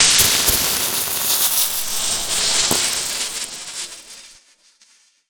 elec_lightning_magic_spell_09.wav